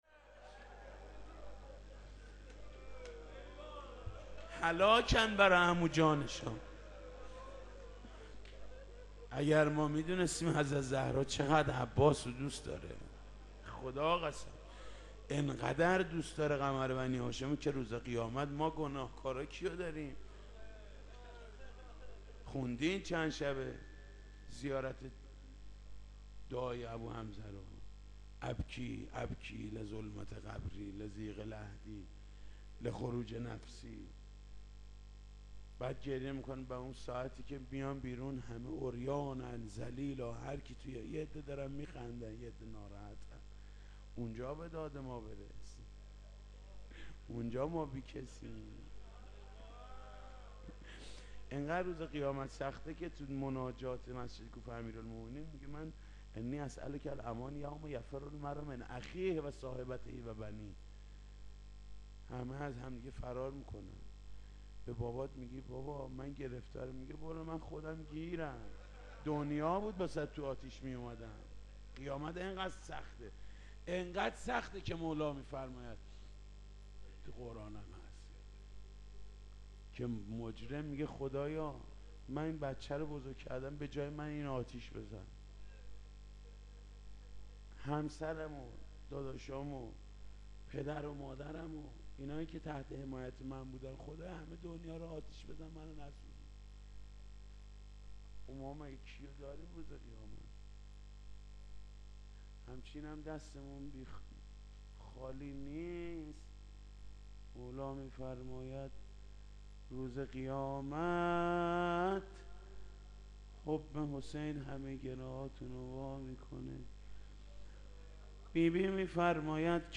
حاج محود کریمی -روضه ام البنین- قسمت سوم-قزوین-آستان مقدس چهارانبیا-موسسه پرچمدار.mp3